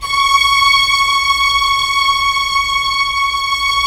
Index of /90_sSampleCDs/Roland - String Master Series/STR_Vlns 1 Symph/STR_Vls1 Symph